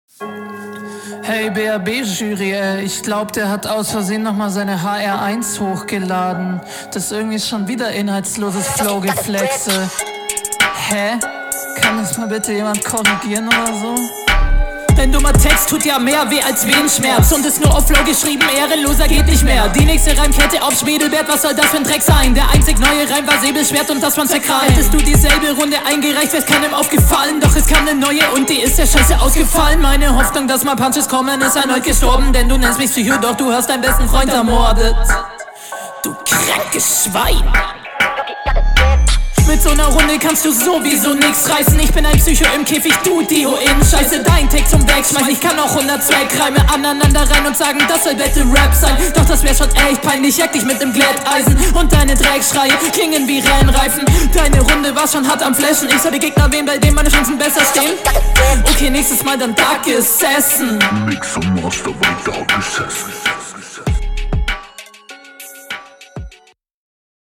Flow: Flowlich hinter deinem Gegner Text: Textlich nicht schlecht holt mich aber nicht ab Soundqualität: …